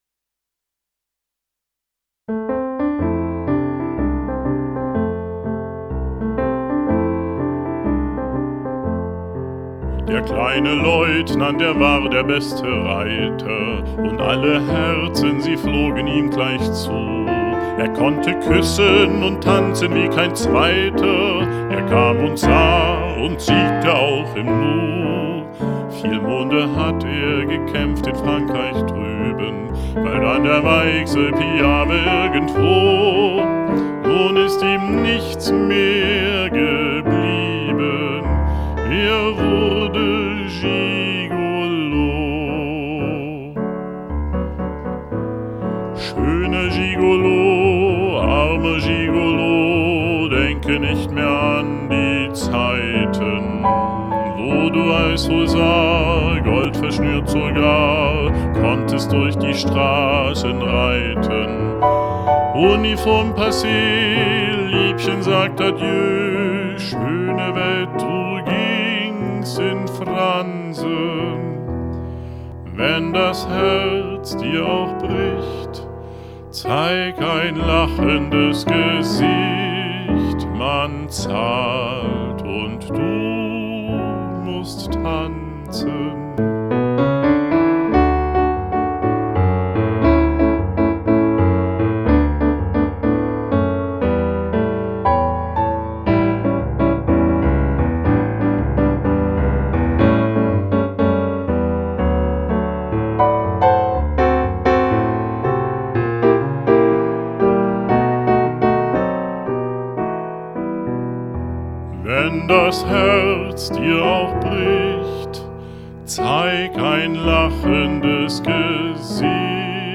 z.T. mit "Grammophon-Special-Effect"